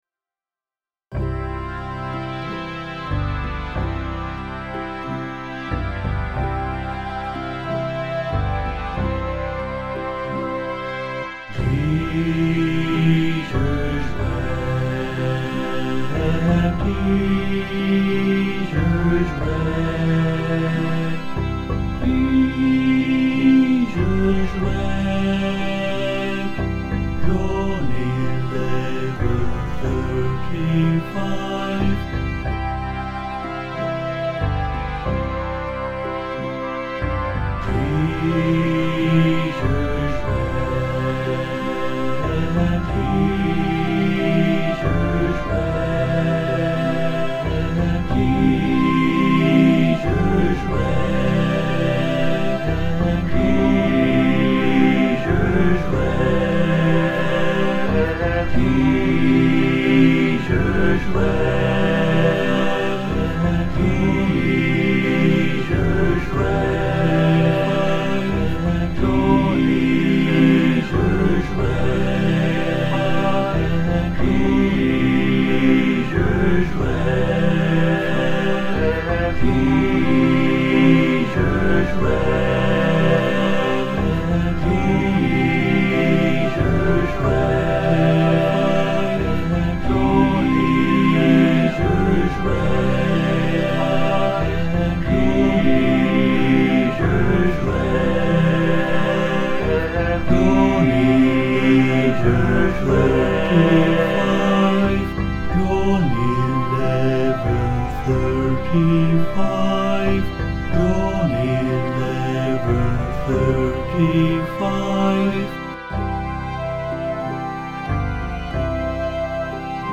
This 4-part round is
voice & guitar